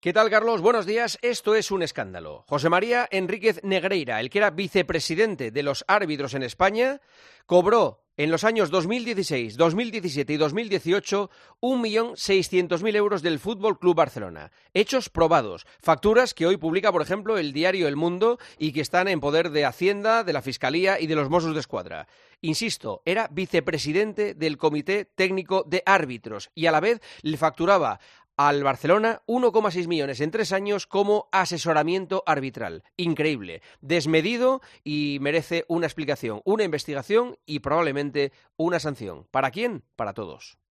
Juanma Castaño le cuenta a Carlos Herrera quién es José María Enríquez Negreira: "Esto es un escándalo"
El presentador de 'El Partidazo de COPE' analiza la actualidad deportiva en 'Herrera en COPE'